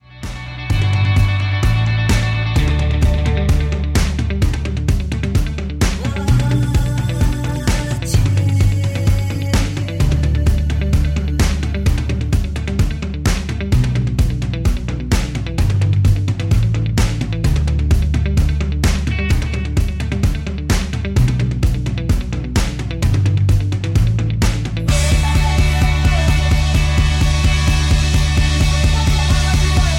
Backing track files: Rock (2136)
Buy With Backing Vocals.
Buy With Lead vocal (to learn the song).